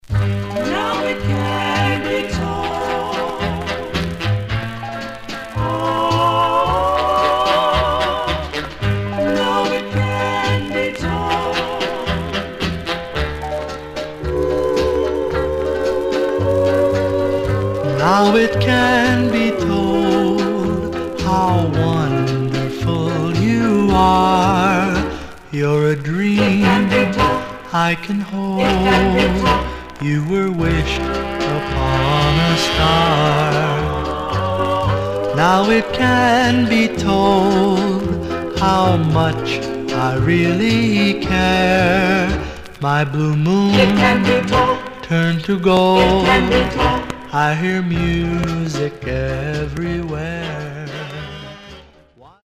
Some surface noise/wear
Mono
Teen